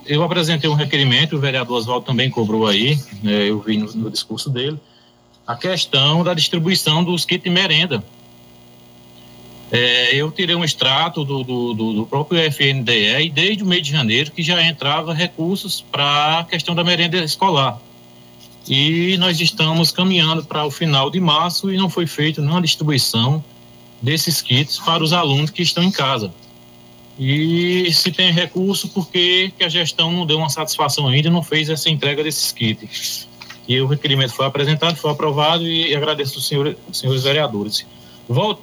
A sessão ordinária remota aconteceu nessa sexta-feira (26) sendo transmitida pela Rádio Conexão FM.